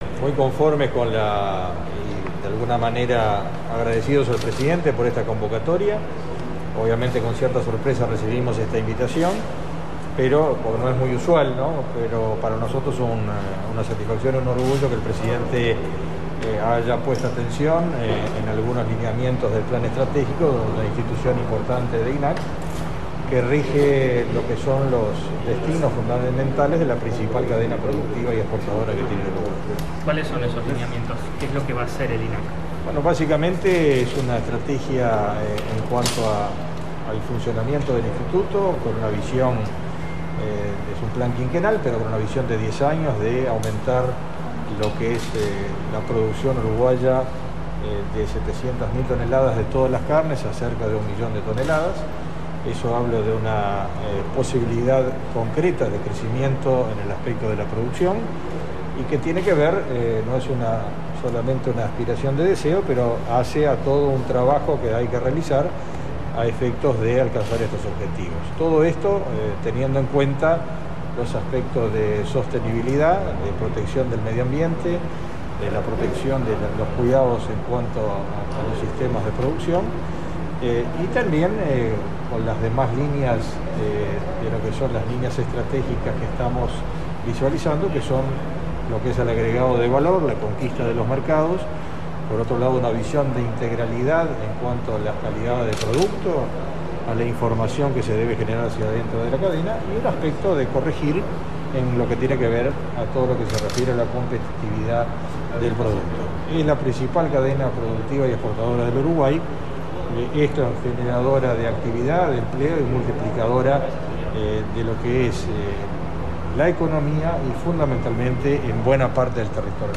AUDIO entrevista
Presidente de INAC. mp3